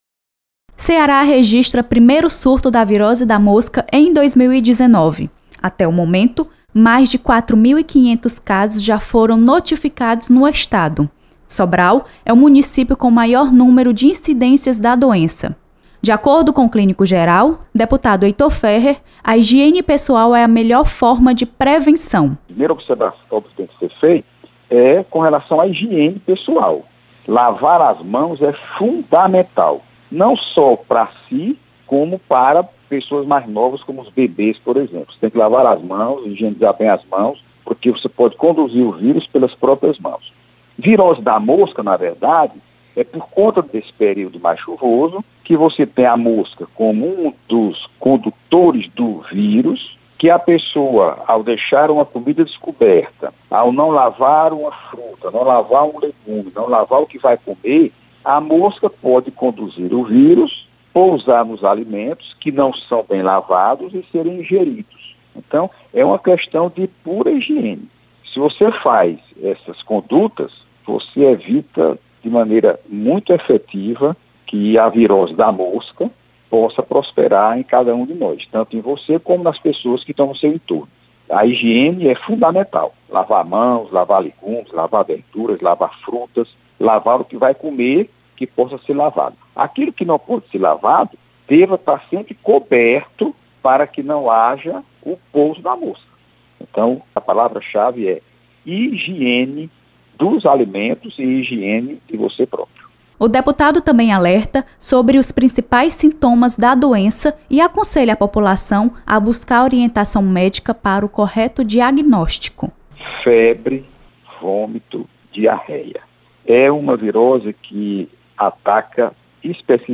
Deputado Heitor Férrer mostra preocupação com surto de virose da mosca e alerta para necessidade de cuidar da higiene pessoal. Repórter